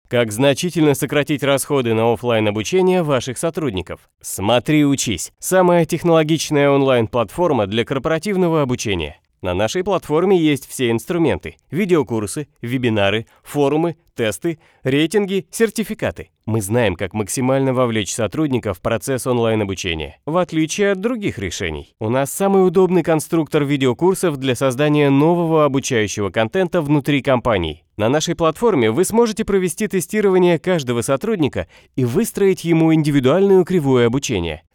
宣传片